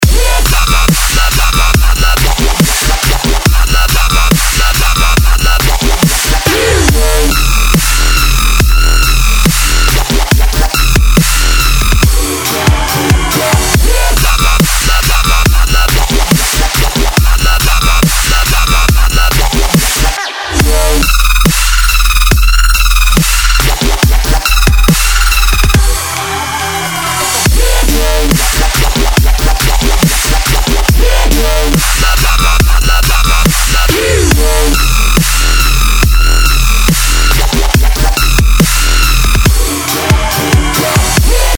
Ощути себя на заводе и во всю орет дрель и болгарка!))